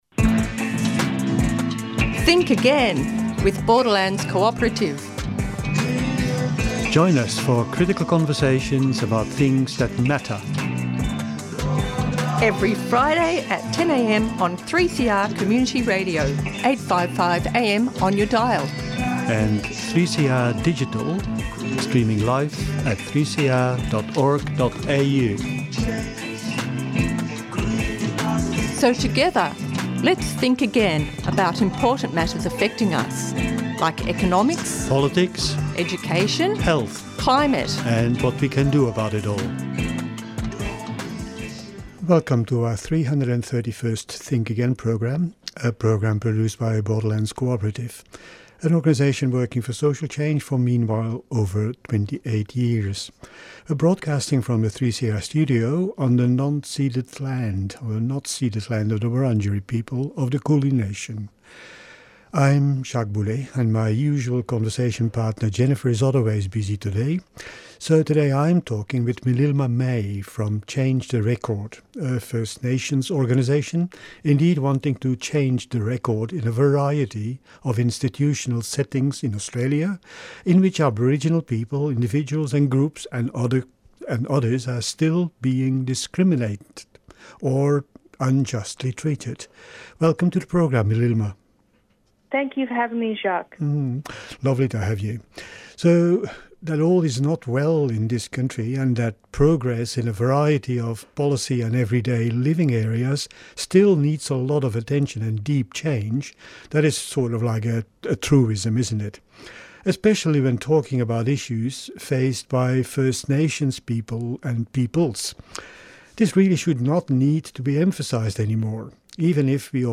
Tweet Think Again Friday 10:00am to 10:30am Think Again offers weekly conversations and reflections about current events, trends and public pronouncements on contemporary and emerging issues.